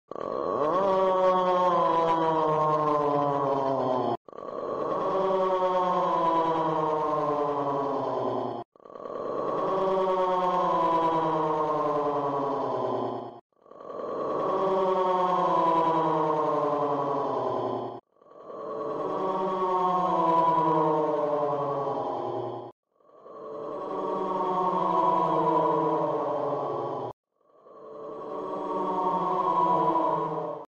Categoria Efeitos Sonoros